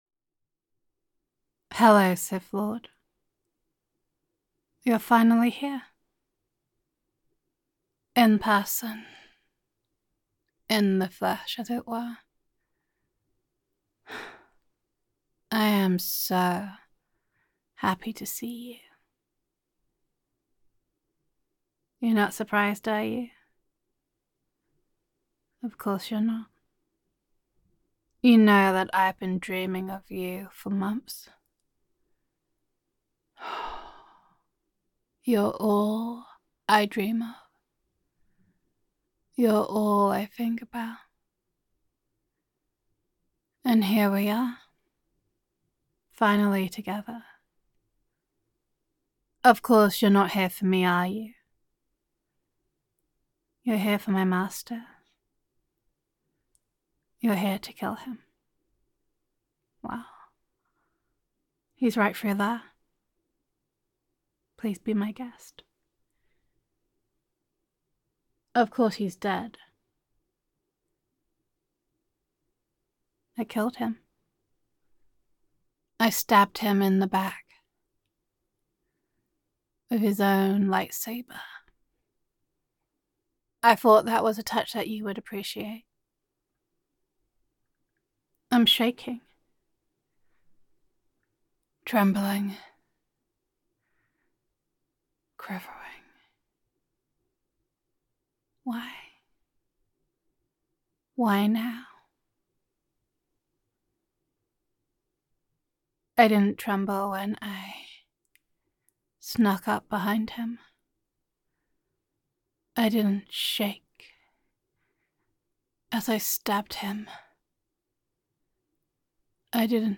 [F4A] Falling [Sith Listener][Jedi Roleplay][Betrayal][Infatuation][Gender Neutral][A Jedi Apprentice Falls in Love and Then Just Falls]